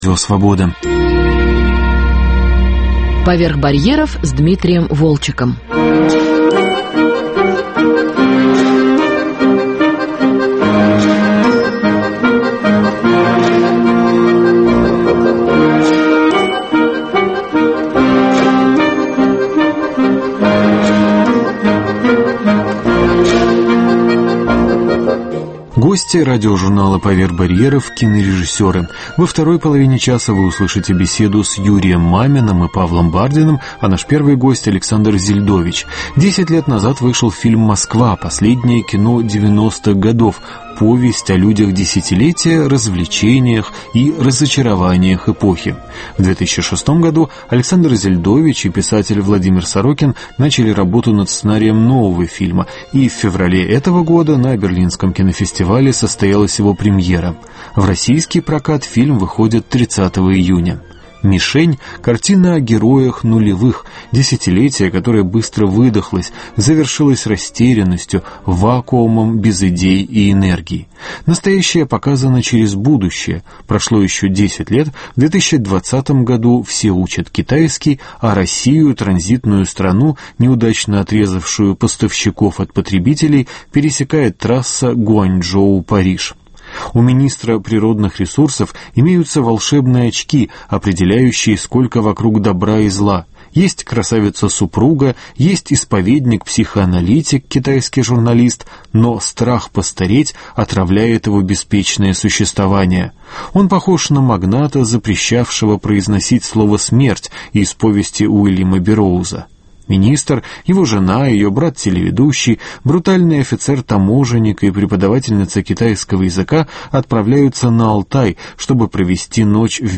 Гости "Поверх барьеров" –кинорежиссеры Александр Зельдович, Юрий Мамин и Павел Бардин.